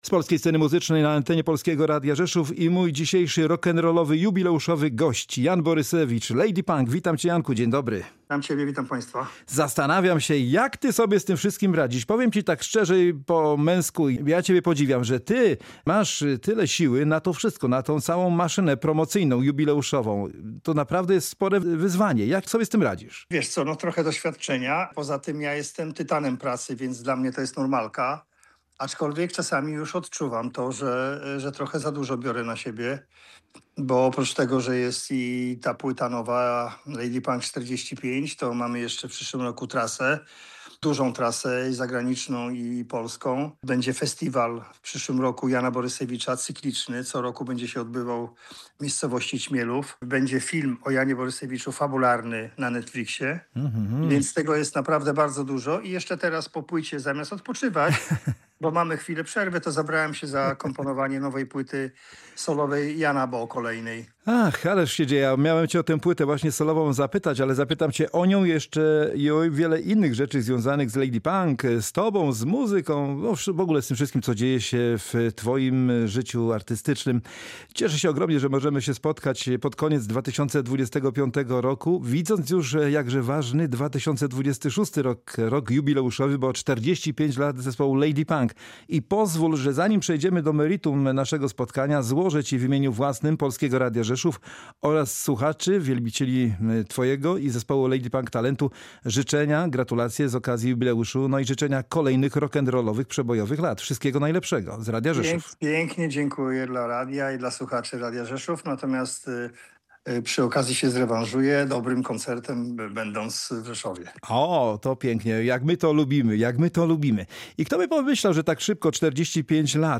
Jan Borysewicz w szczerej rozmowie o życiu i muzyce w Polskim Radiu Rzeszów
Genialny muzyczny samouk, jeden z najwybitniejszych gitarzystów w Polsce, autor tekstów i wokalista – ale przede wszystkim kompozytor – w specjalnym, jubileuszowym wywiadzie opowiadał o swojej karierze.